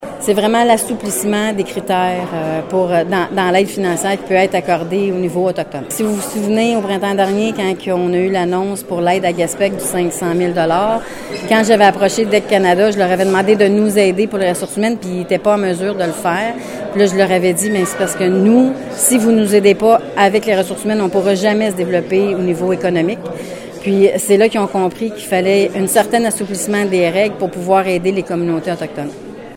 De plus, 2,7 millions de dollars viendront appuyer le développement économique et social lié aux pêches des communautés autochtones.  La chef du Conseil de la Nation Micmac de Gespeg, Manon Jeannotte, salue cette annonce :